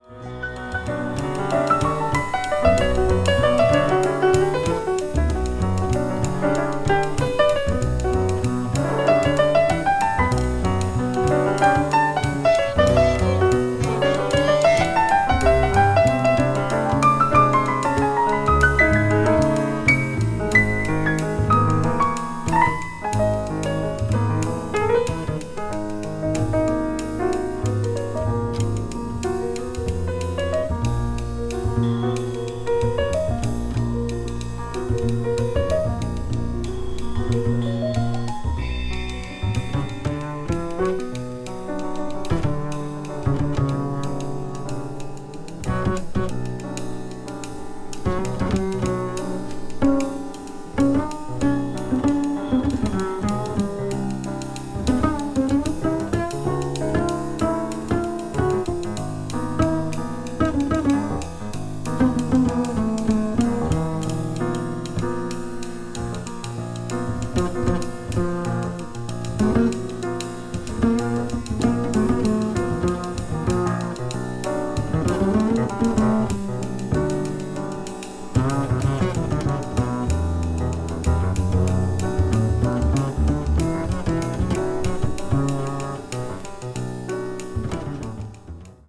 media : EX/EX(some slightly noises.)
contemporary jazz   deep jazz   spiritual jazz